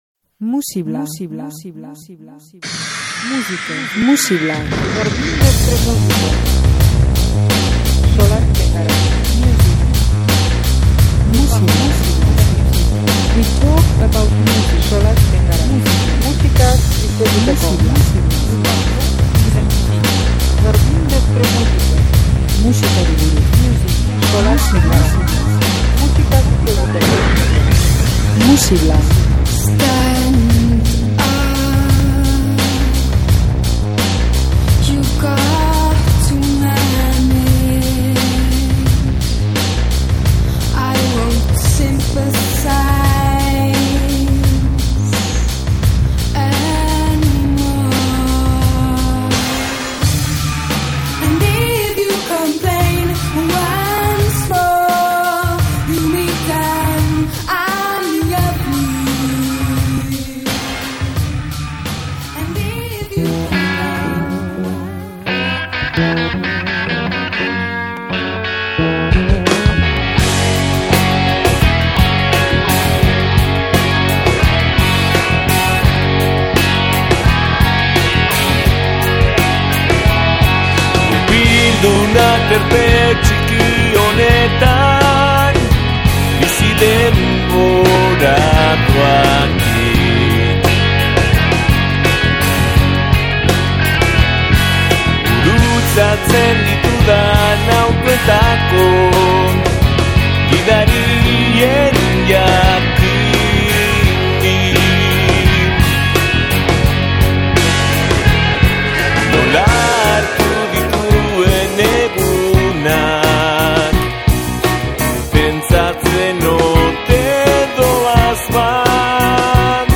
pop dotorea eta melodia ederrak